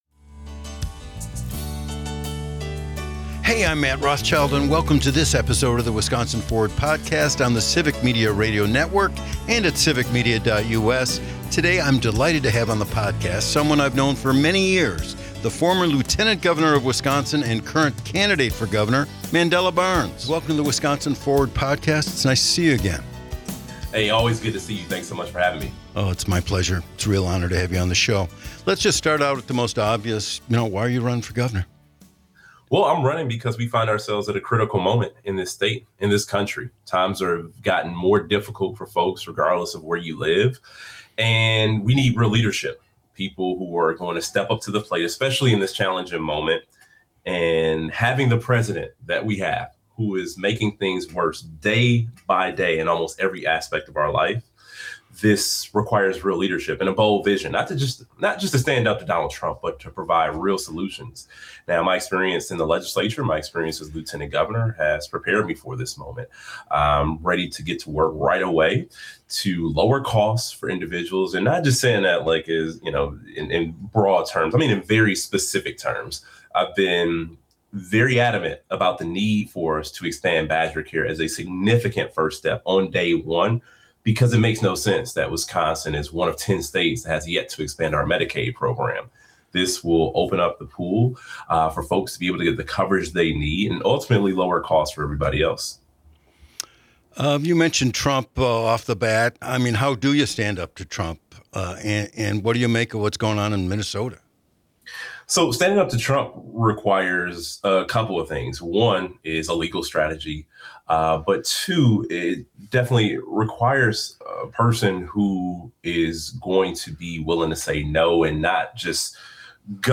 An Interview with Mandela Barnes - Civic Media